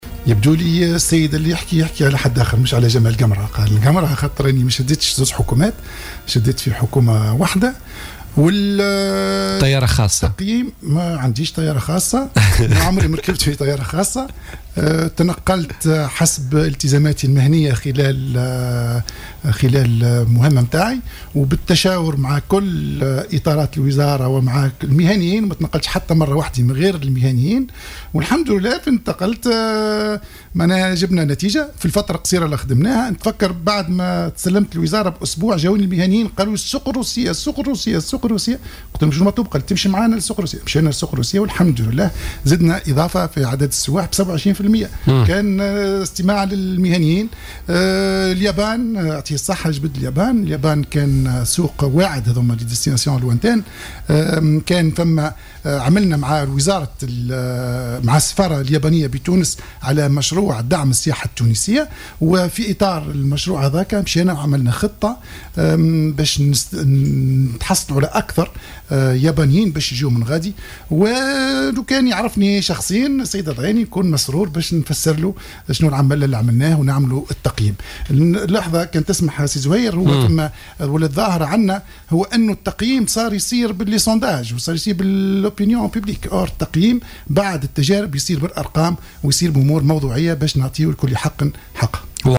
نفى وزير السياحة الأسبق، جمال قمرة ضيف برنامج "بوليتيكا" الاتهامات التي وجهت له بخصوص إهداره للمال العام عندما كان وزيرا.